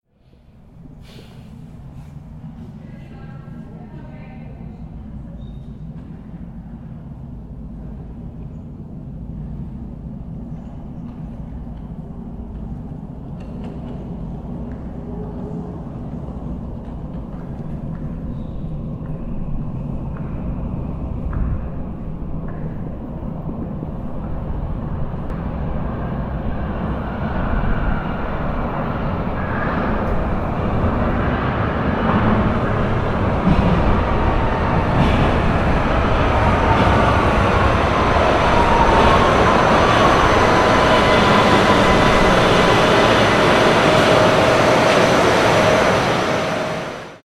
جلوه های صوتی
دانلود صدای آمدن مترو از ساعد نیوز با لینک مستقیم و کیفیت بالا
برچسب: دانلود آهنگ های افکت صوتی حمل و نقل